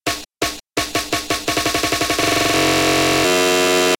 In this example I have used a snare again, and am repeating it faster and faster to illustrate the glitchy sounds you get once you reach a certain speed.